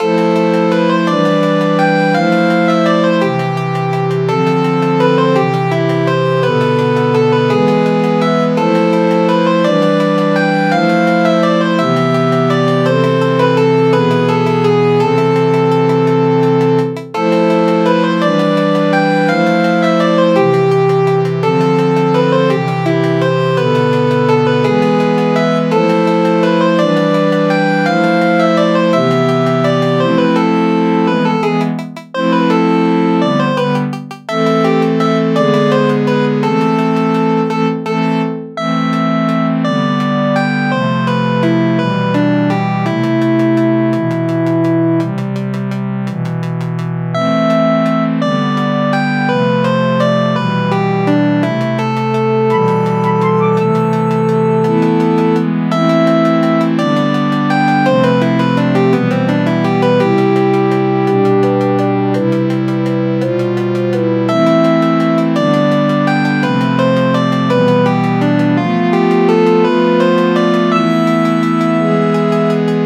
無限ループノスタルジック、さびしい、ピコピコ